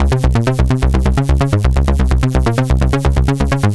AC_ARPBassB_128-C.wav